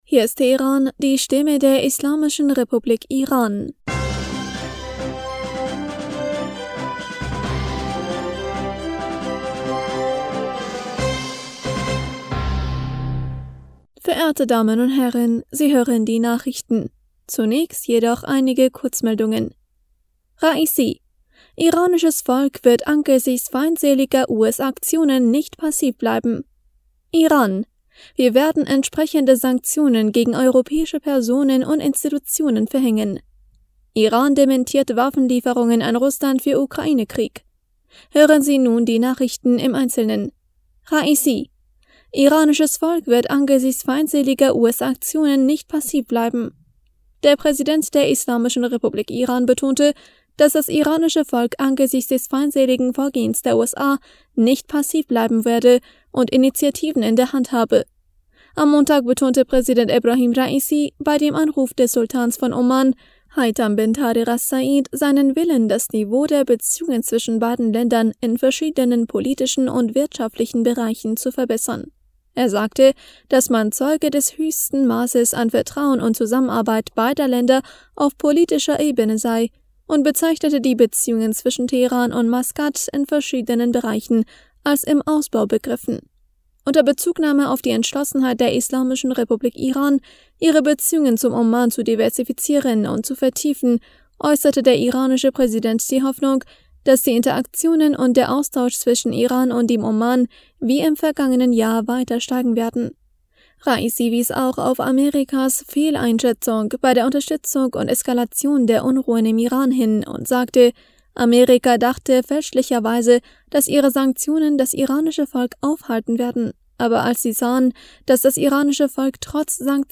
Nachrichten vom 18. Oktober 2022
Die Nachrichten von Dienstag, dem 18. Oktober 2022